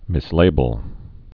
(mĭs-lābəl)